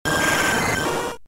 Cri de Nosferalto K.O. dans Pokémon Diamant et Perle.